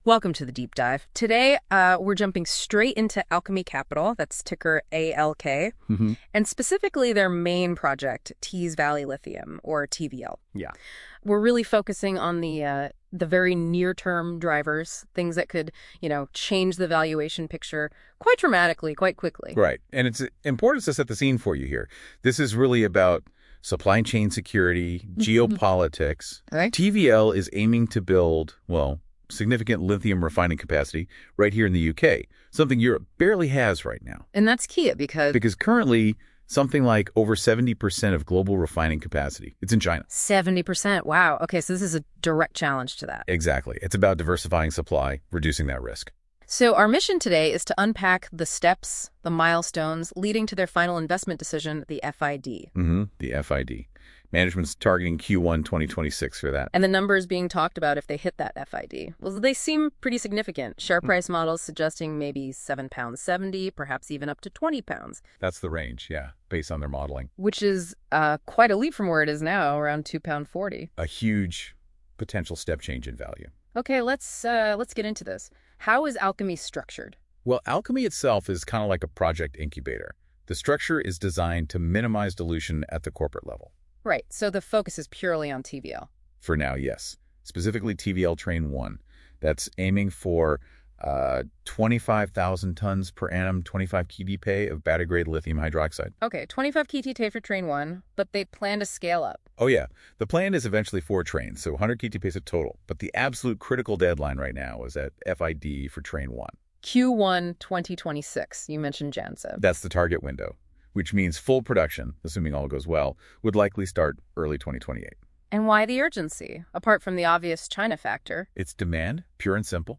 These AI generated audio overviews are an interesting way to listen to a summary of the publications and presentations by ALK/TVL